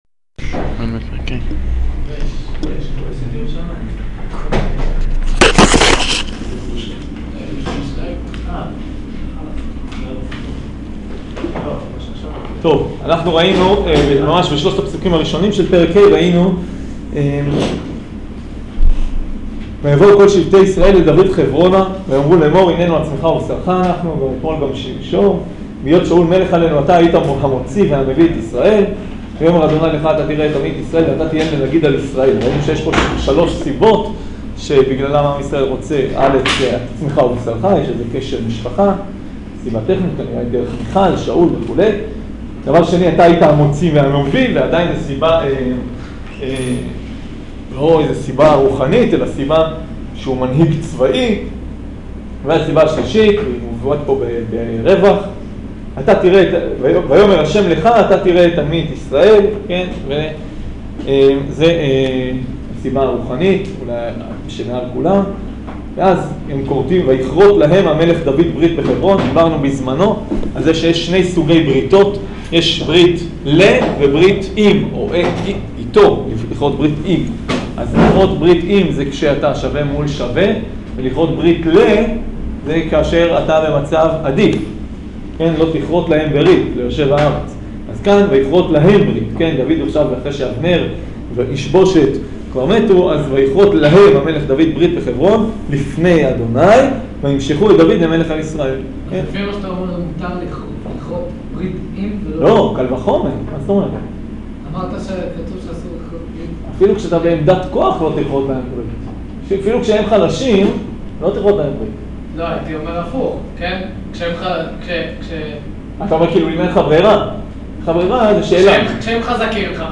שיעור שמואל ב' פרק ה